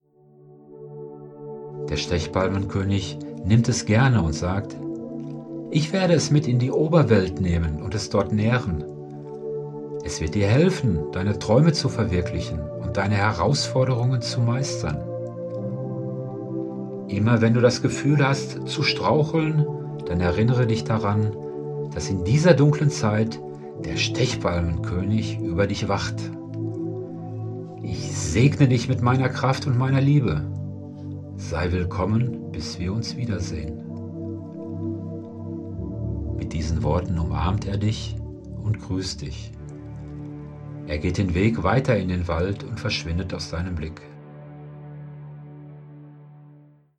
Meditationen zu den keltischen Festen für Hingabe, Klarheit und Kraft